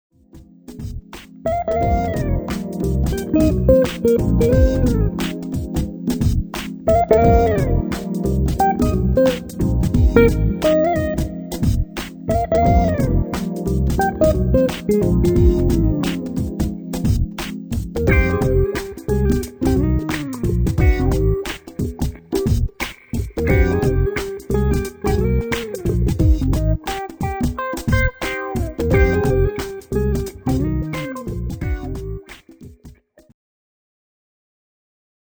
and contemporary jazz.
His nylon string guitar sings over original
hypnotic grooves, complimenting many venues